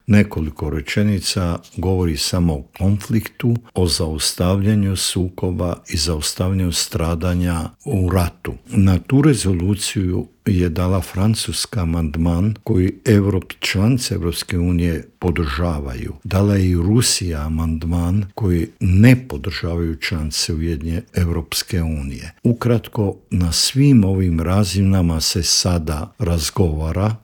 O tome se proteklih dana razgovaralo i u Vladi, a posebni savjetnik premijera Andreja Plenkovića i bivši ministar vanjskih i europskih poslova Mate Granić u Intervjuu Media servisa rekao je da je Ured predsjednika informiran o svemu što radi Vlada RH.